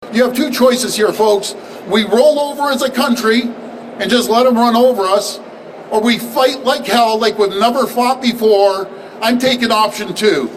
Speaking at a Toronto mining conference fresh off his re-election, Ford said he is considering “Buy Ontario” legislation to prioritize domestic products in government procurement.